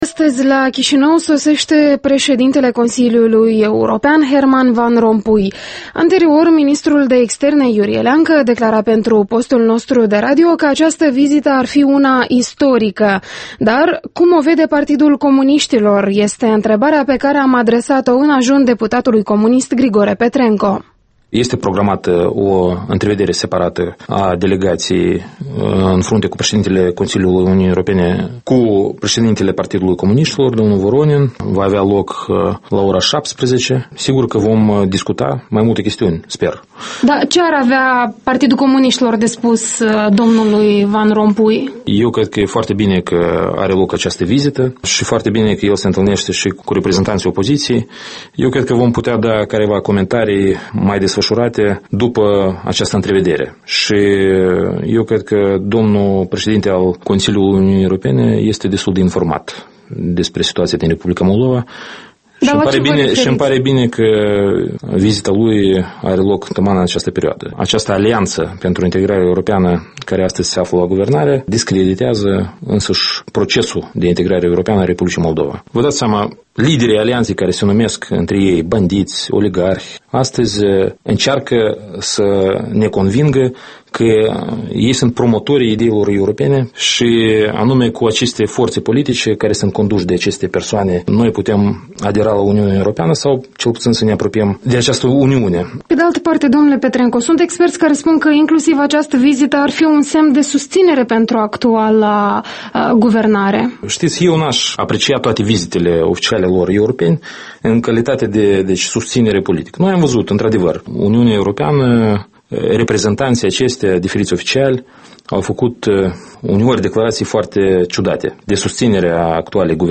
Interviul matinal la Europa Liberă: cu Grigore Petrenco